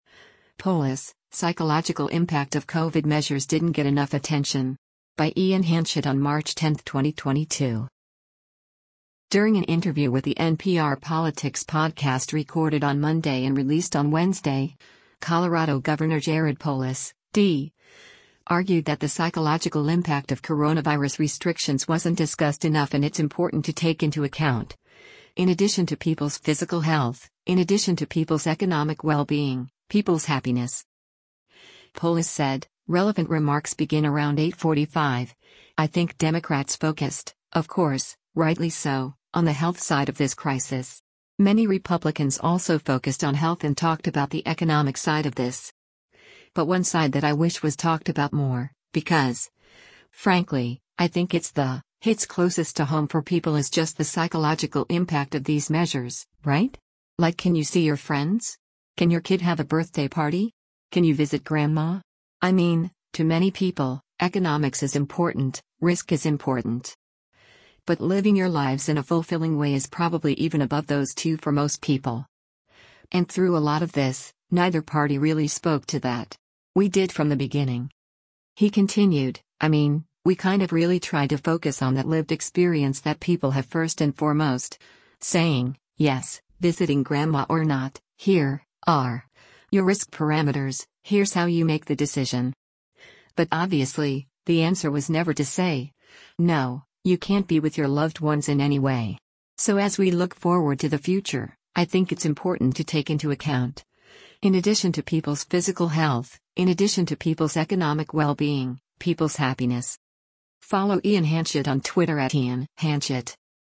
During an interview with “The NPR Politics Podcast” recorded on Monday and released on Wednesday, Colorado Gov. Jared Polis (D) argued that the psychological impact of coronavirus restrictions wasn’t discussed enough and “it’s important to take into account, in addition to people’s physical health, in addition to people’s economic well-being, people’s happiness.”